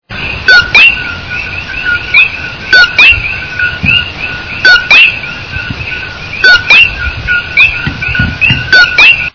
Coqui_frog.mp3